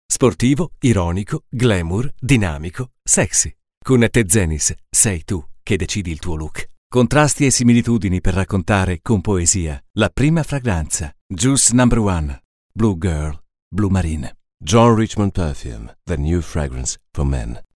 Italiana voice talent.
Kein Dialekt
Sprechprobe: Werbung (Muttersprache):